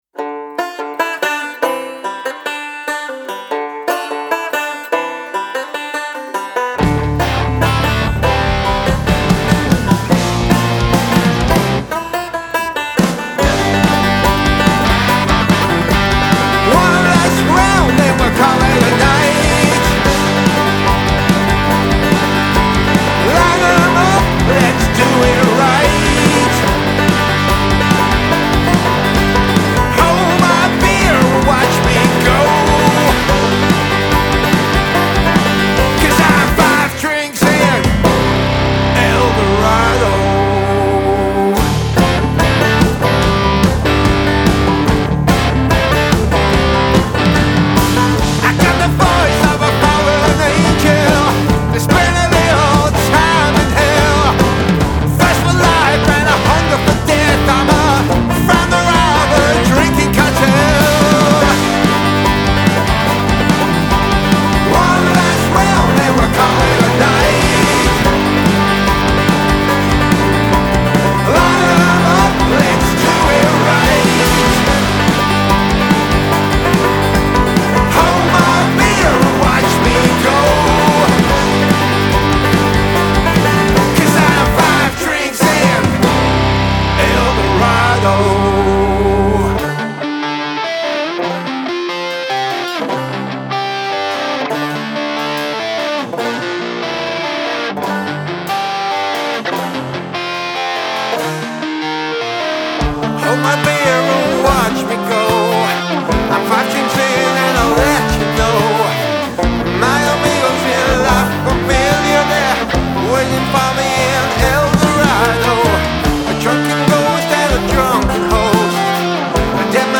Male Vocal, Guitar, Banjo, Bass Guitar, Drums